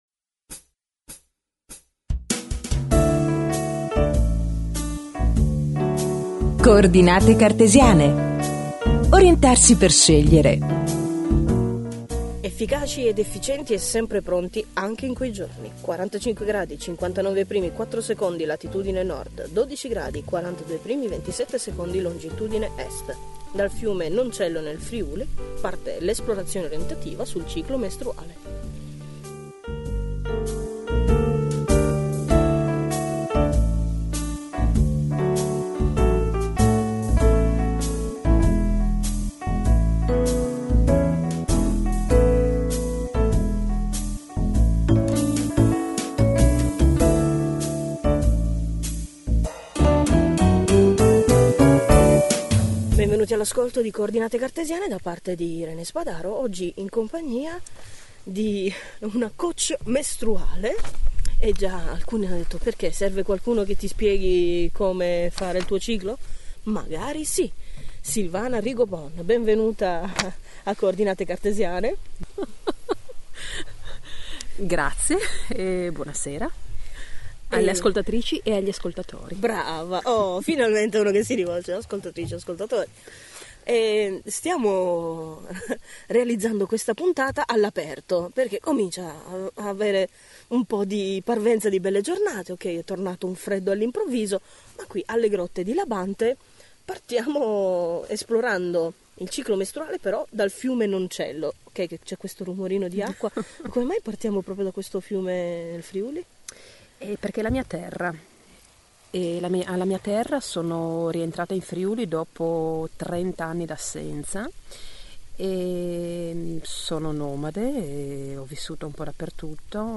Una conversazione per celebrare, connettersi e condividere la ciclicità mestruale, un sapere per uomini e donne
Un passaggio veloce da Bologna in Appennino, con una intervista all’aperto.